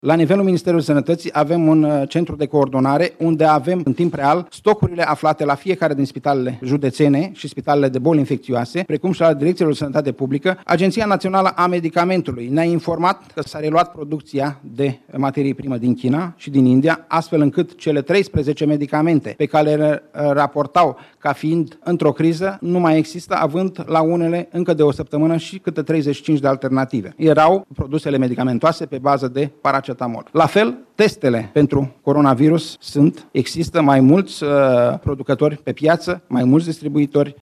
Acesta a vorbit și despre stocurile de medicamente și echipamente sanitare din spitale: